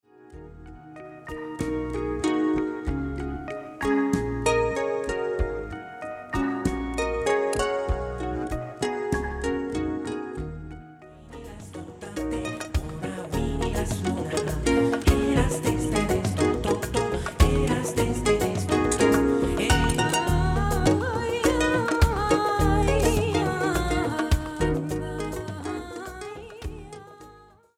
electracoustic pedal harp, gu-cheng & more...
Recorded and mixed at the Sinus Studios, Bern, Switzerland